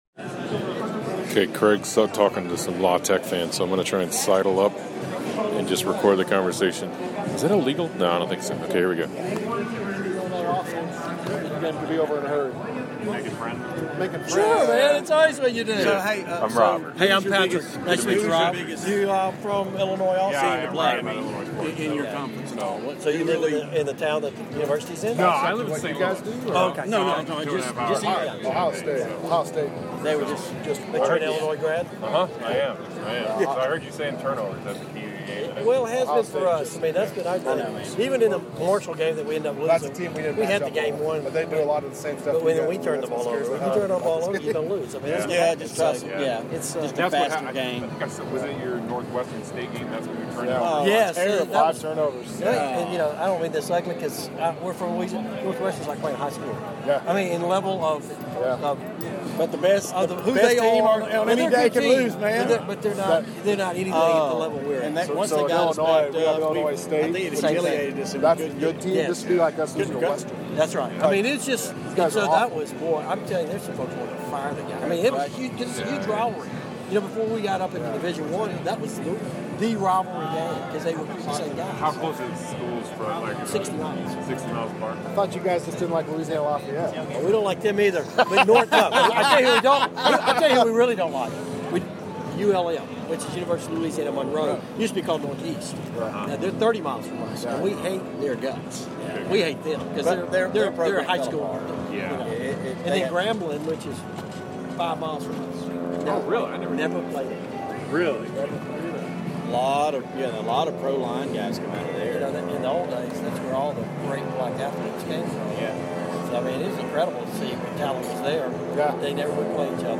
Chatting with La Tech fans